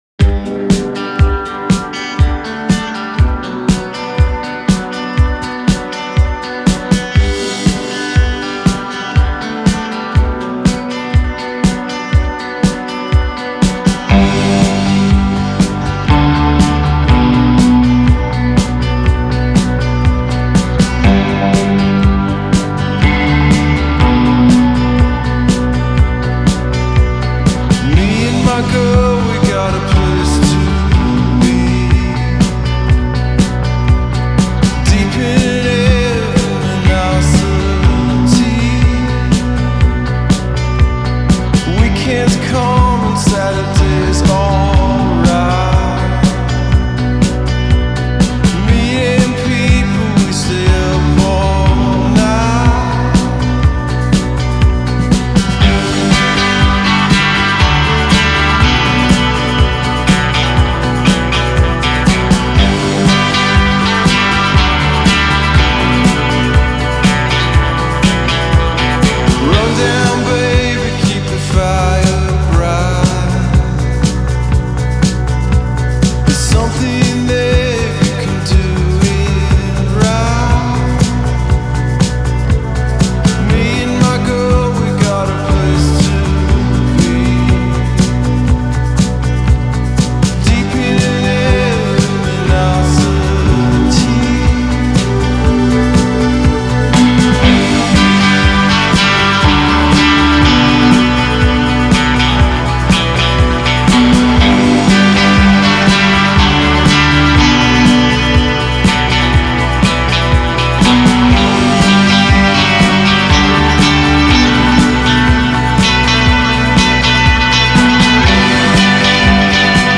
Genre: Indie Pop, Rock, Alternative